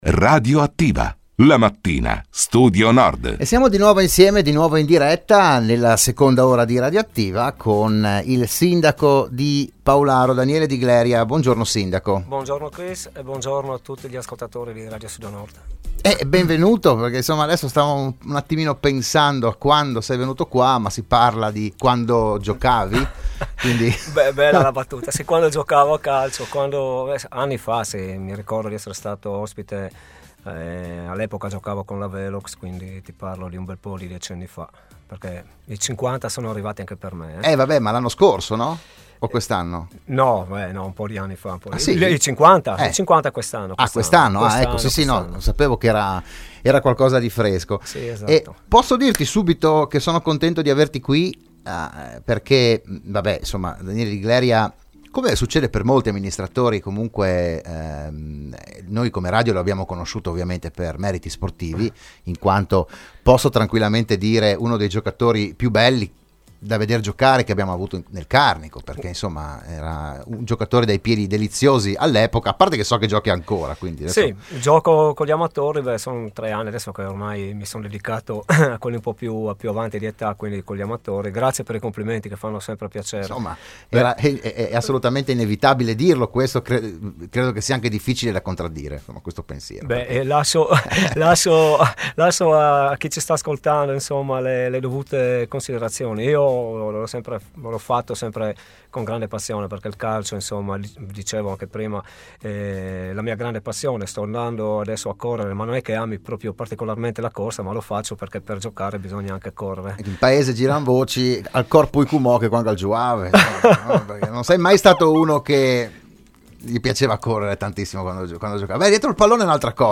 Ogni settimana il primo cittadino di un comune dell’Alto Friuli sarà ospite in studio, in diretta (anche video sulla pagina Facebook di RSN), per parlare del suo territorio, delle problematiche, delle iniziative, delle idee, eccetera.
Al terzo appuntamento della nuova serie ha partecipato il sindaco di Paularo Daniele Di Gleria.